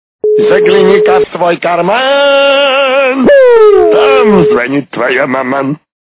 При прослушивании Загляни ка в свой карман. - Там звонит твоя маман. качество понижено и присутствуют гудки.